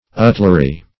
utlary - definition of utlary - synonyms, pronunciation, spelling from Free Dictionary Search Result for " utlary" : The Collaborative International Dictionary of English v.0.48: Utlary \Ut"la*ry\, n. Outlawry.